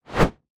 whip.mp3